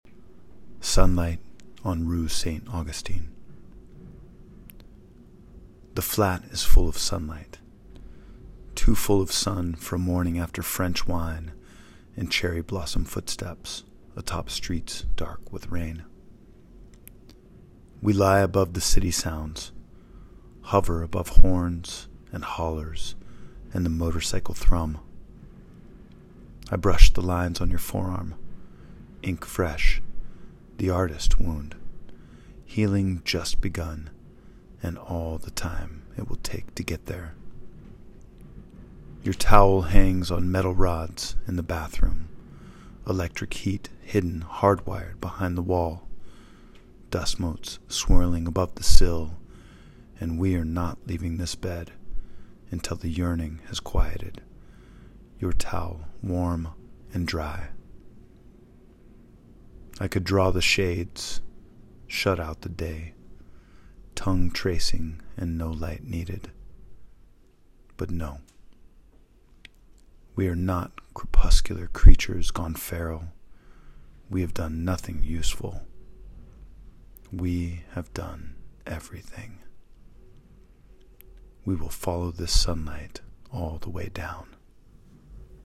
Press Play to hear the author read their work.